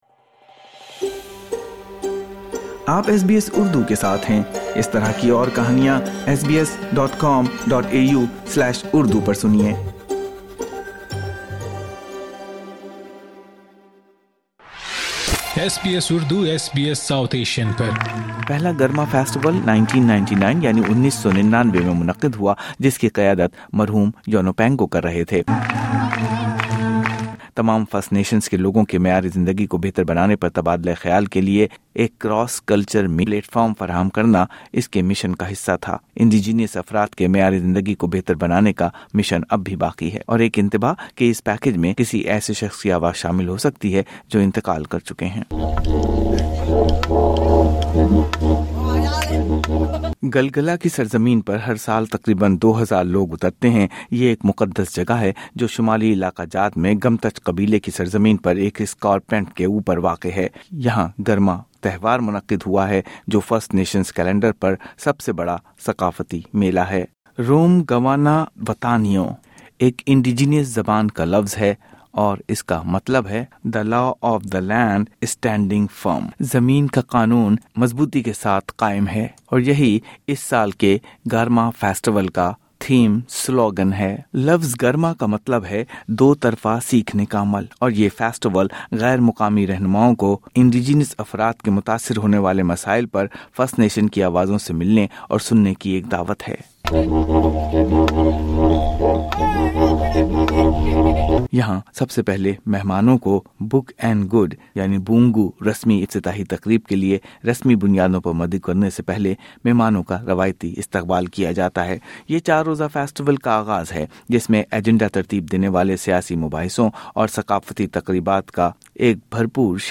گارما فیسٹیول پہلی بار 1999 میں مرحوم یونوپنگو کی قیادت میں منعقد ہوا تھا۔ یہ ایک بین الثقافتی اجلاس ہے جہاں رہنما جمع ہوتے ہیں تاکہ تمام فرسٹ نیشنز لوگوں کے معیارِ زندگی کو بہتر بنانے پر بات چیت کی جا سکے۔ ایک انتباہ: اس رپورٹ میں ایک ایسے شخص کی آواز شامل ہے جو اب اس دنیا میں نہیں رہا۔